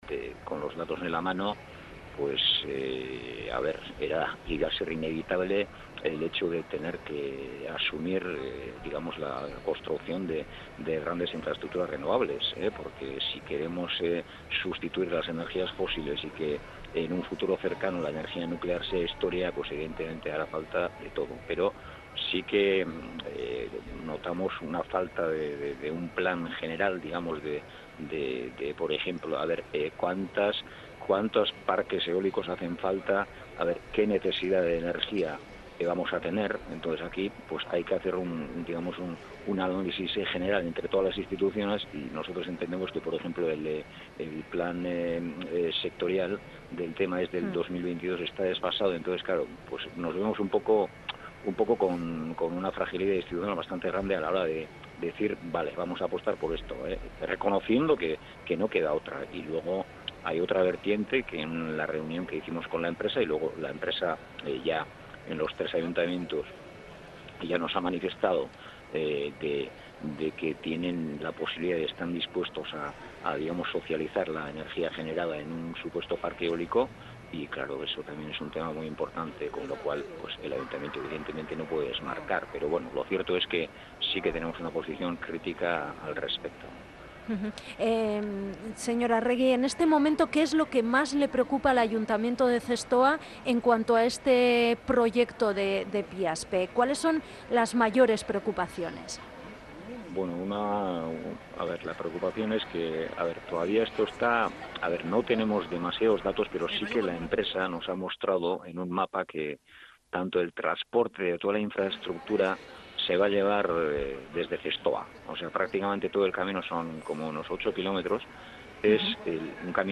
El alcalde de Zestoa Mikel Arregi ha matizado esta mañana su postura en torno a la instalación en Piaspe de un parque eólico por parte de la noruega Statkraft.